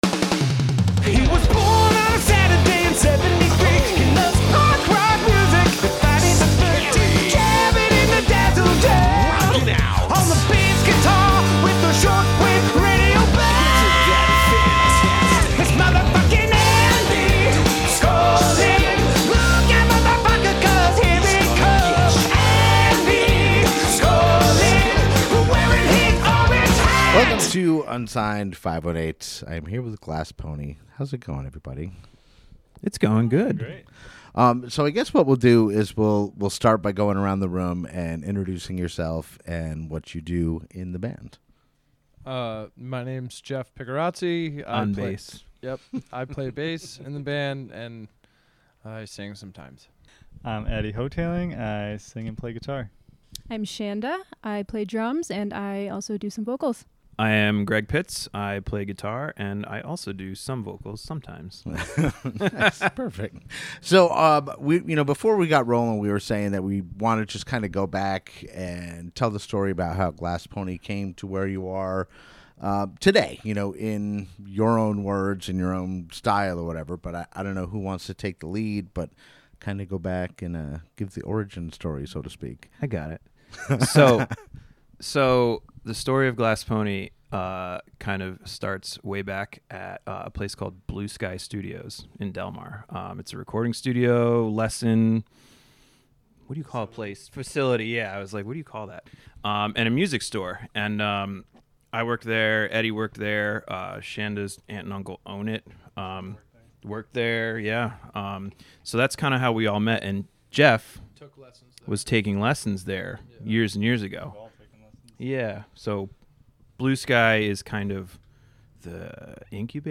On this episode Glass Pony stops by the Dazzle Den and we discuss the origin of this great band. We learn insights into where the name came from, as well as some of their writing and recording processes.